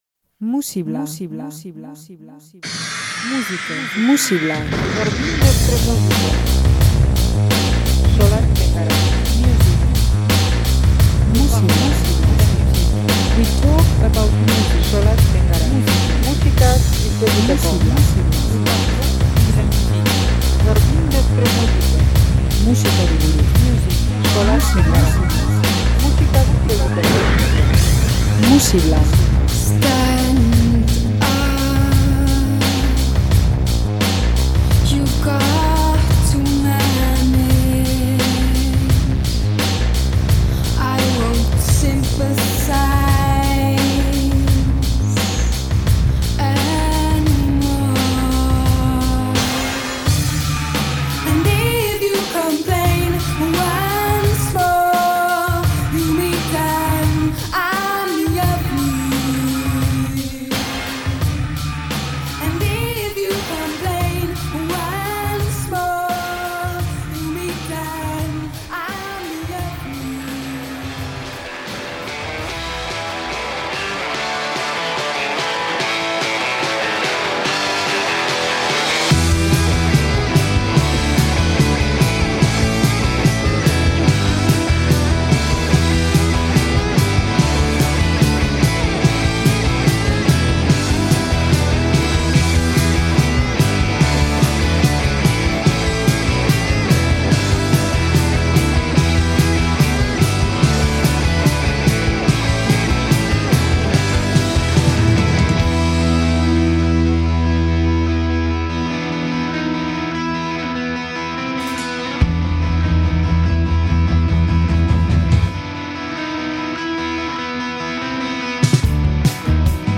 Giro desberdinak bizi dira disko honetako 12 abestietan.
Soinu retroak eta giro lanbrotsuak. Chanson Française